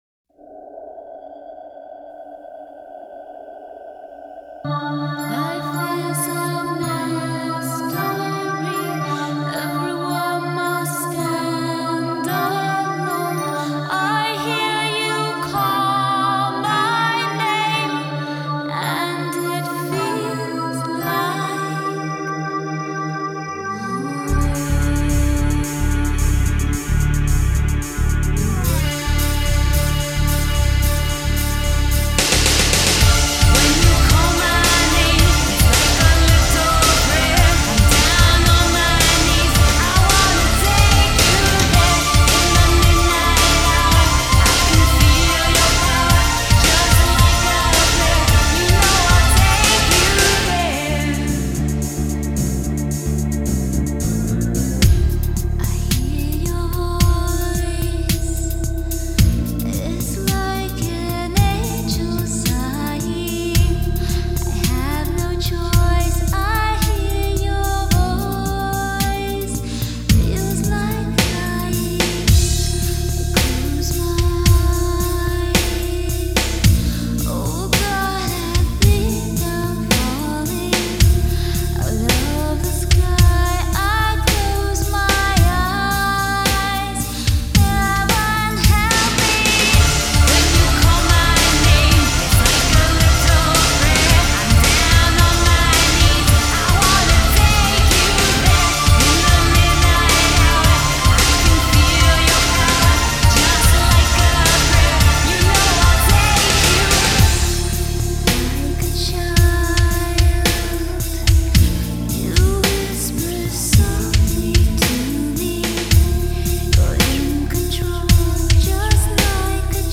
Vocal
Bass, Sequence
Drums, Sequence